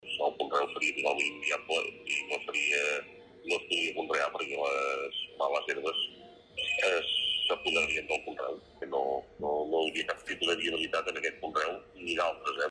Ho ha explicat en declaracions a Ràdio Capital.